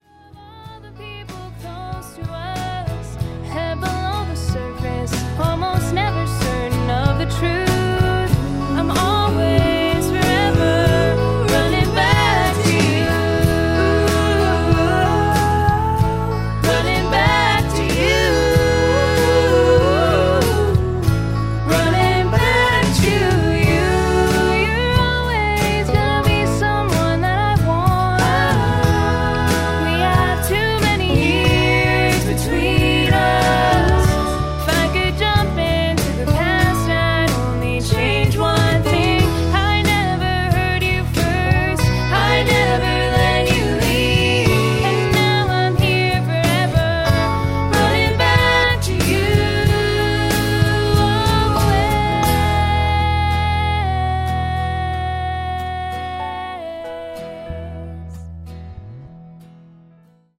A beautifully stripped-down cover
lead vocals
with a warm, acoustic feel